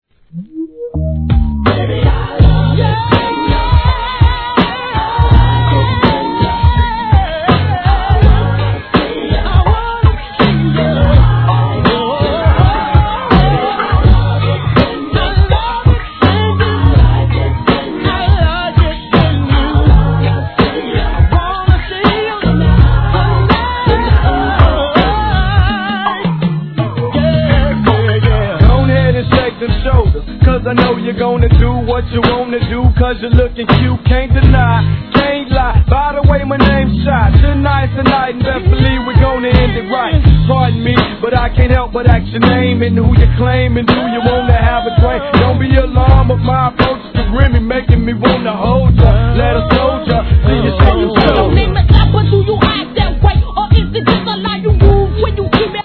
G-RAP/WEST COAST/SOUTH
まさにマッタリにドンピシャリのテンポで刻むBEAT、そして厚いPIN POINTベース、コーラス・フック。。。